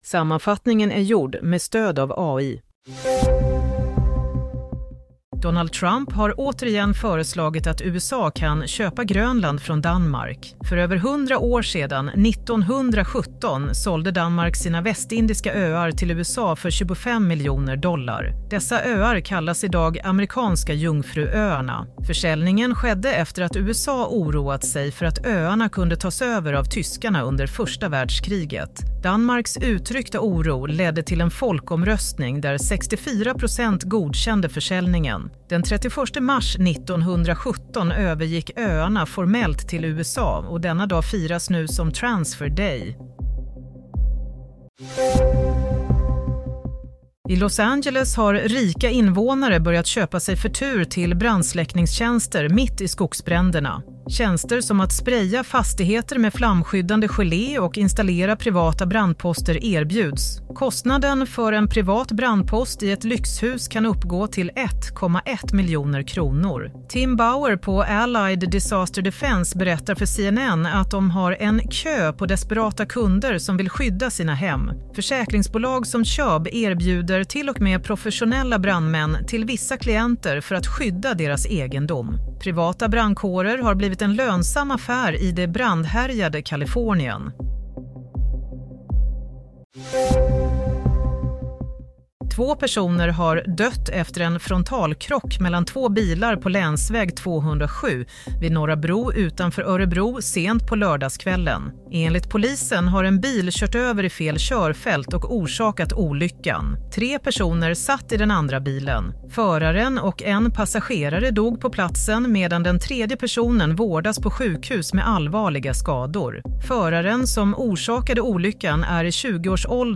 Nyhetssammanfattning - 12 januari 07:00